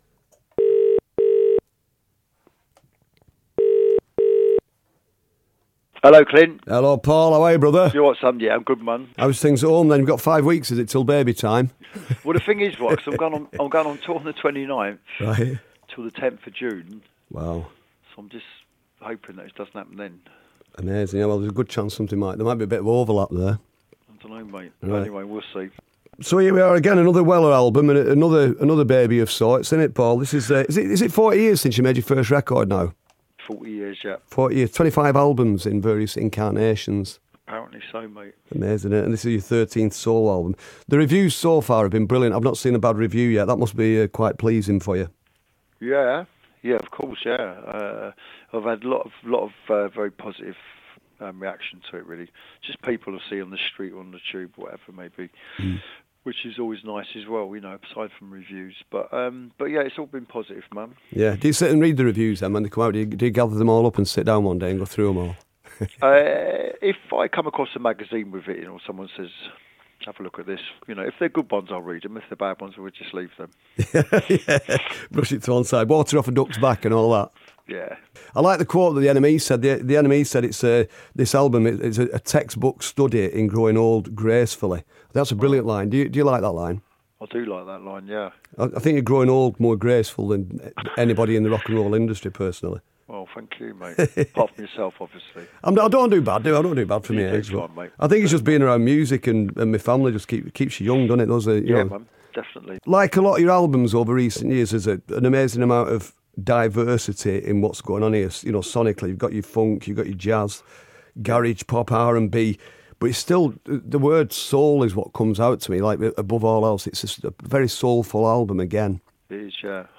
Clint Boon talking to Paul Weller